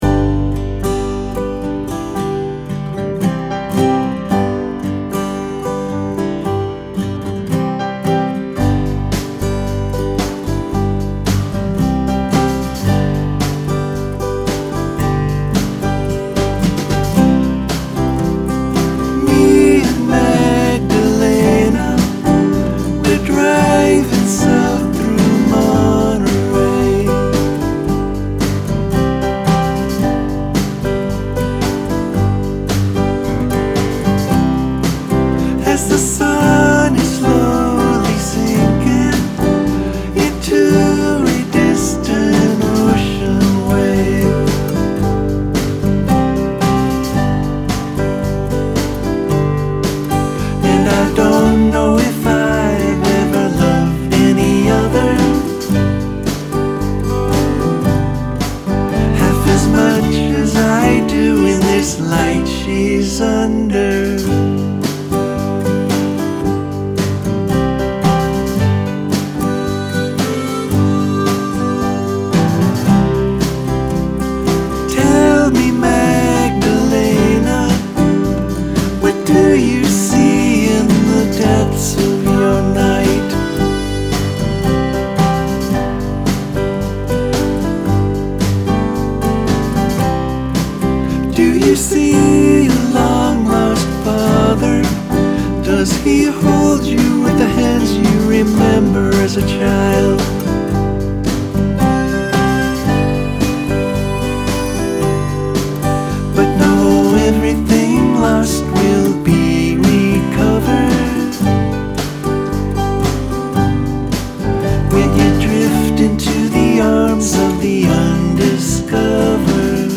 The next day I started on this cover.